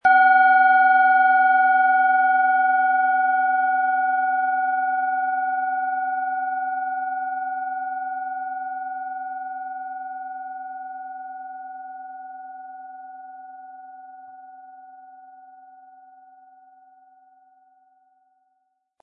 Planetenschale® Herzbereich öffnen & Stark und kräftig fühlen mit DNA-Ton, Ø 10,9 cm, 260-320 Gramm inkl. Klöppel
Planetenton 1
Um den Original-Klang genau dieser Schale zu hören, lassen Sie bitte den hinterlegten Sound abspielen.
Durch die traditionsreiche Fertigung hat die Schale vielmehr diesen kraftvollen Ton und das tiefe, innere Berühren der traditionellen Handarbeit
Der Klöppel lässt die Klangschale voll und harmonisch tönen.